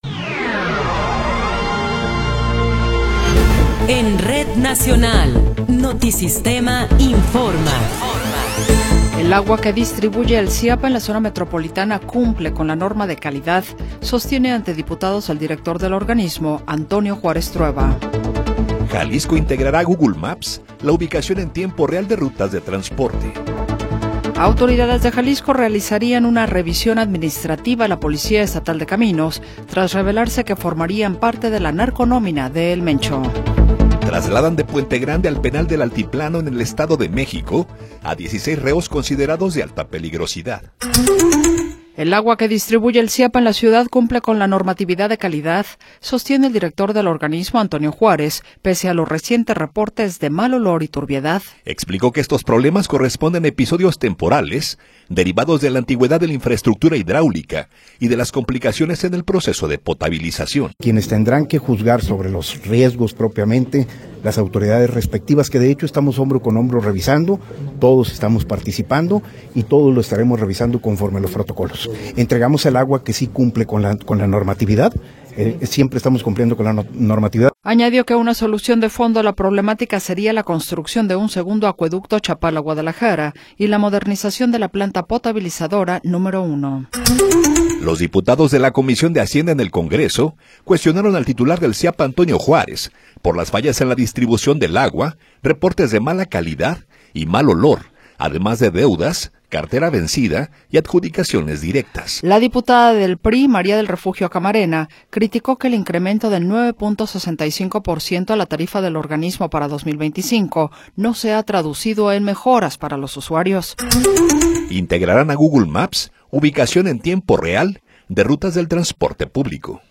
Noticiero 20 hrs. – 12 de Marzo de 2026
Resumen informativo Notisistema, la mejor y más completa información cada hora en la hora.